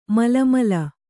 ♪ mala mala